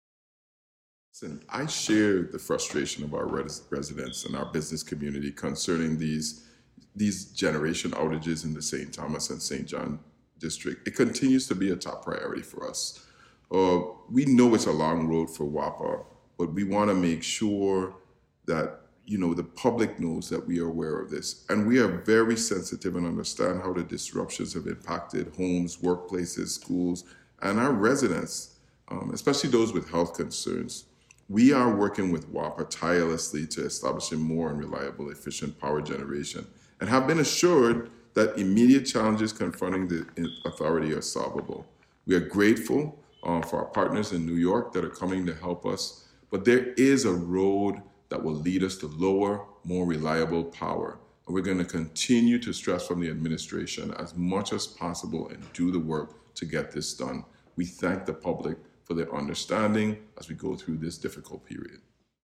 Governor Bryan Issues Statement on WAPA Blackouts in St. Thomas, St. John
Listen and download audio file of Governor Bryan’s statement